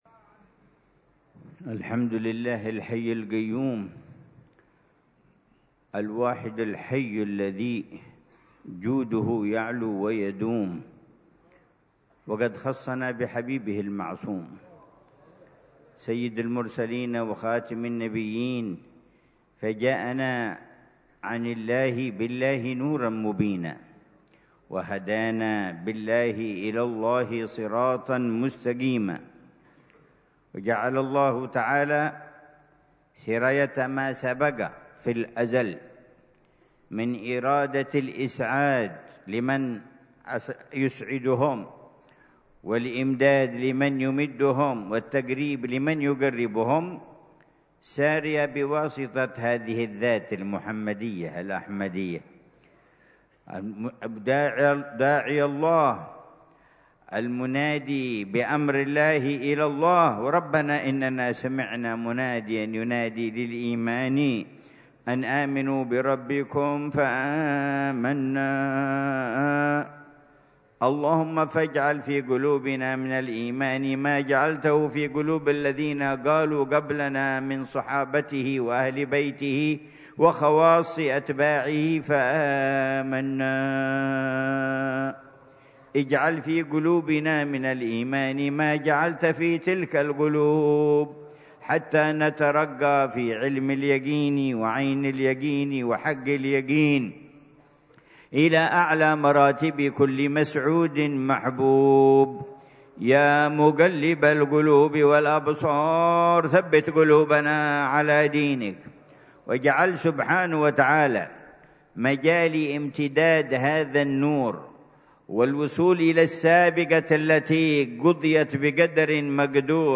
محاضرة العلامة الحبيب عمر بن محمد بن حفيظ ضمن سلسلة إرشادات السلوك في دار المصطفى، ليلة الجمعة 27 جمادى الأولى 1446هـ، بعنوان: